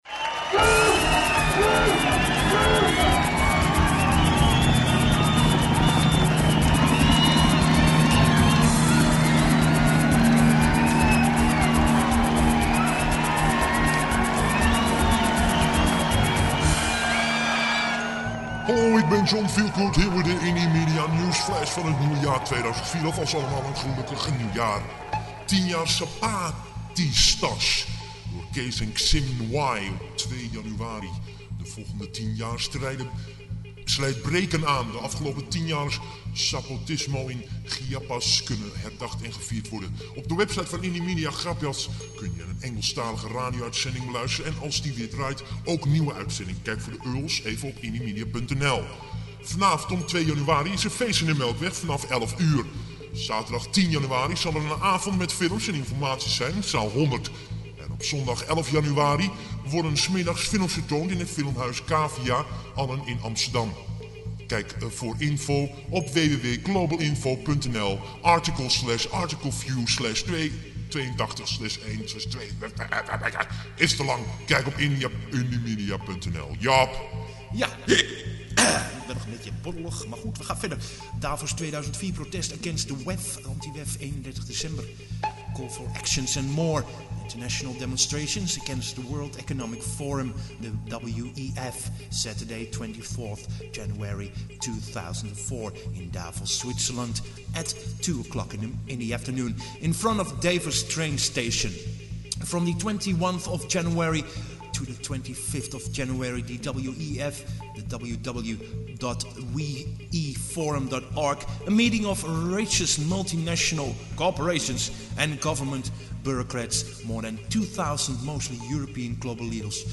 News_flash -
4freeradio: 96 bittekes in hifi stereo met een tijd van 00:08.10